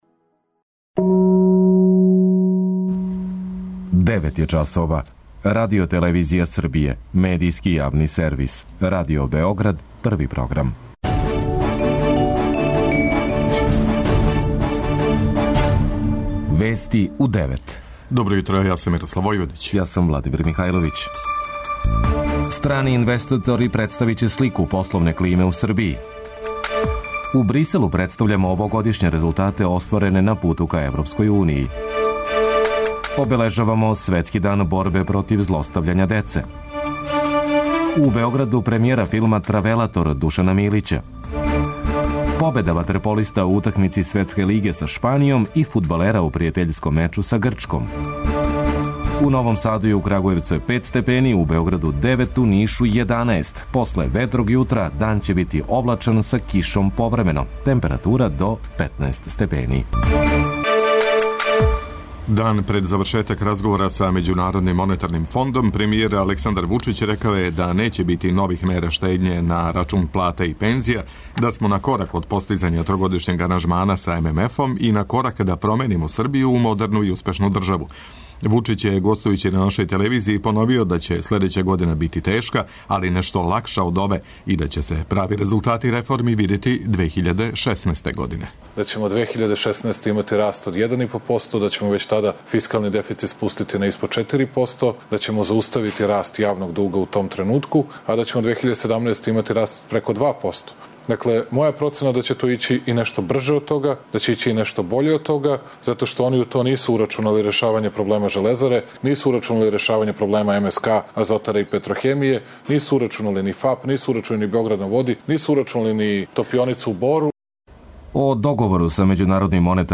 преузми : 10.20 MB Вести у 9 Autor: разни аутори Преглед најважнијиx информација из земље из света.